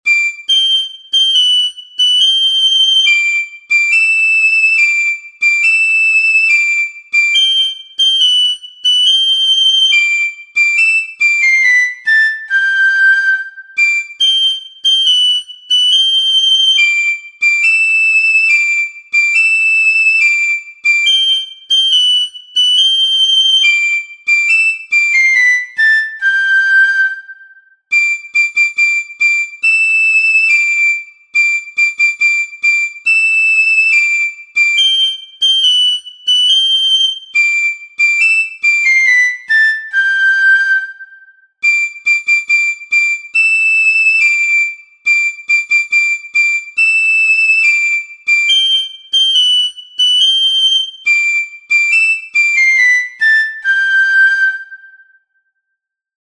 Morgestraich_mit_4ter_Stimme-Piccolofloete_1.mp3